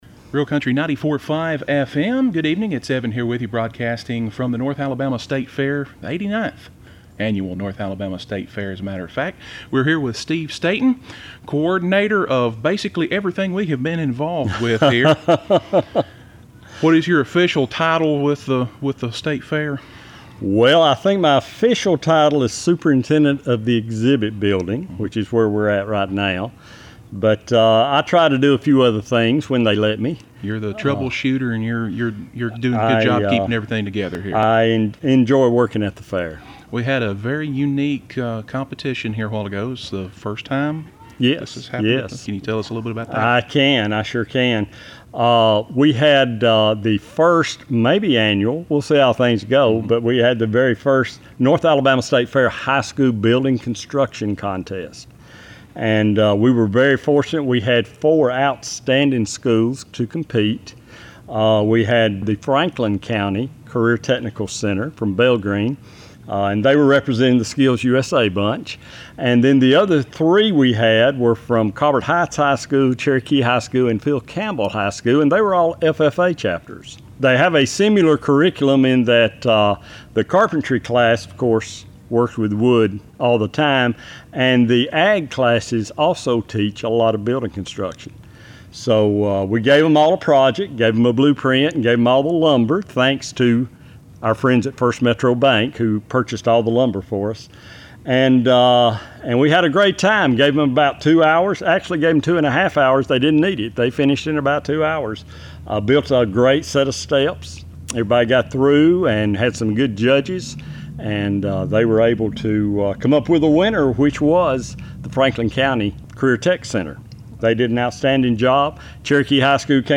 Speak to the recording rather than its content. Continuing the tradition, WNRA Transcriptions are the programs and features we have recorded in our studios for on-demand listening here on our website.